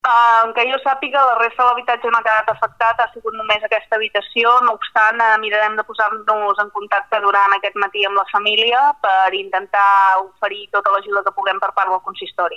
L’alcaldessa de Mont-Ras, Vanessa Peiró, ha explicat en declaracions a Ràdio Palafrugell que va ser l’infant qui es va despertar per la calor i va avisar els seus pares.